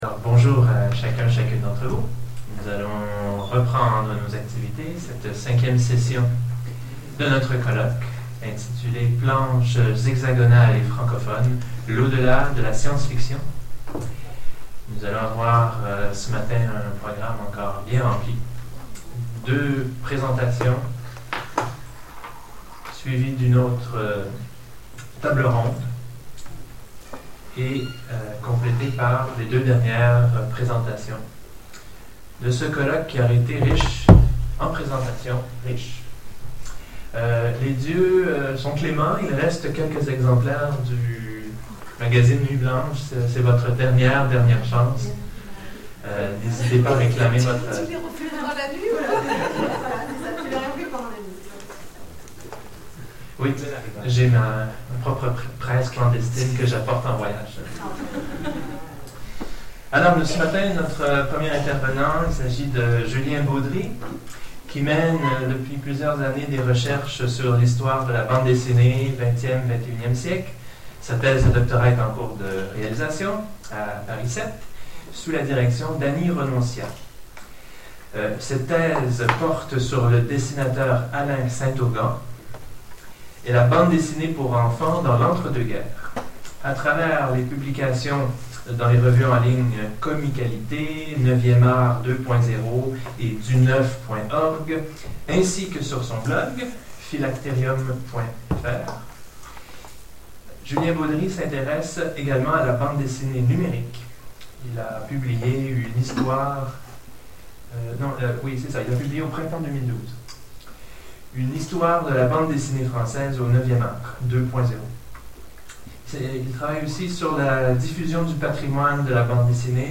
Colloque Les Dieux cachés de la SF francophone : 5e session